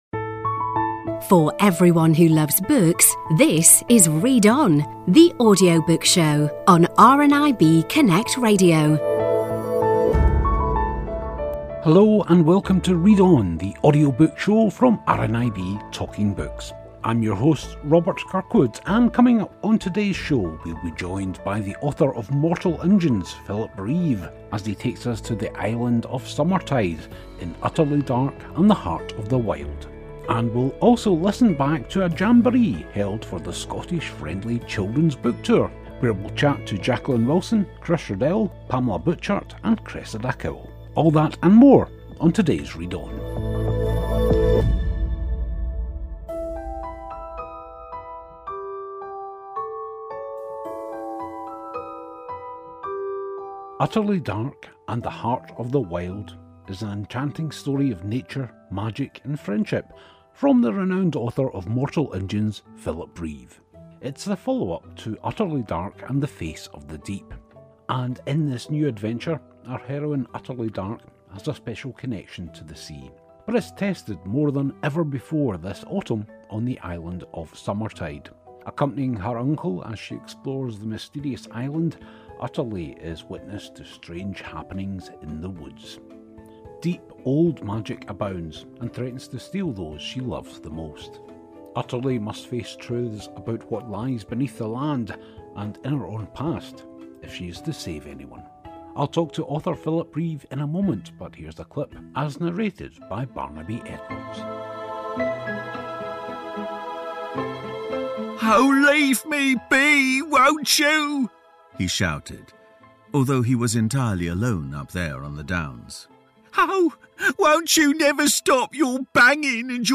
Philip Reeve, author of Mortal Engines, tells us all about his new book Utterly Dark and the Heart of the Wild plus we listen back to the Scottish Friendly Children's Book Tour Jamboree with Jacqueline Wilson, Chris Riddell, Pamela Butchart and Cressida Cowell.